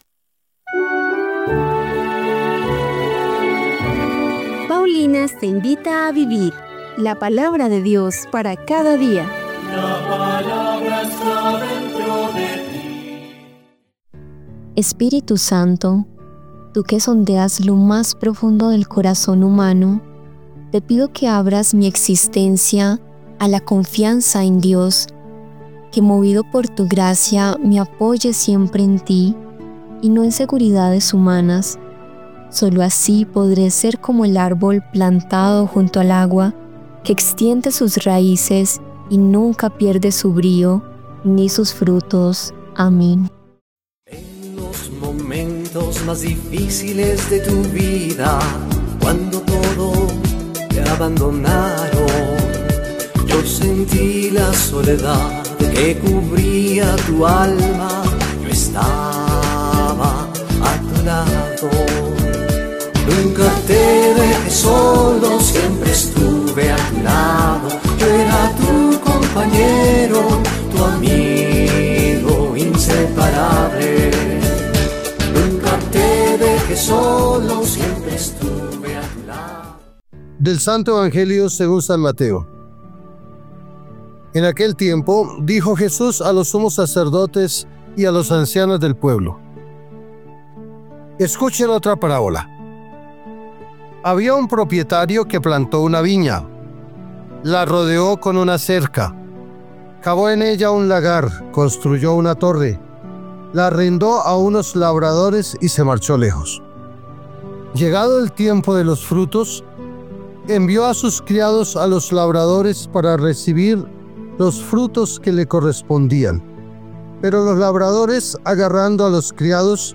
Lectura del libro del Génesis 17, 3-9